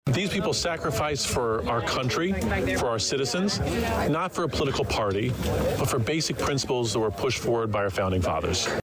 State Treasurer Frerichs said afterwards that those who lost their lives, sacrificed for our country, not one or the other side of the aisle.